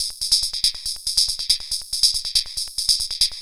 tx_perc_140_fizzhats.wav